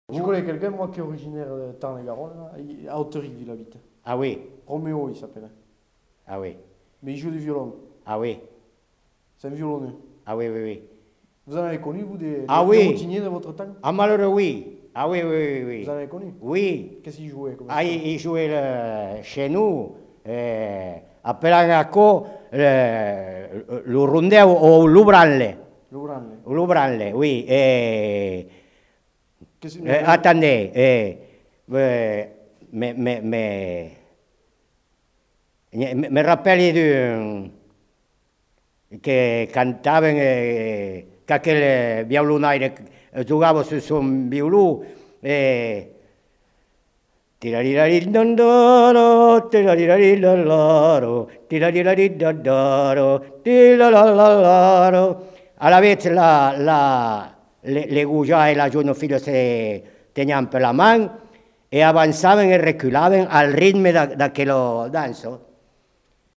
Aire culturelle : Haut-Agenais
Lieu : Portet-sur-Garonne
Genre : chant
Effectif : 1
Type de voix : voix d'homme
Production du son : fredonné
Danse : rondeau